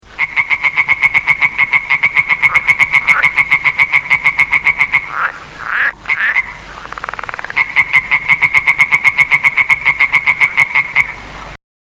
Rosničky
Hlas rosničky [mp3, 238 kB]
rosnicka.mp3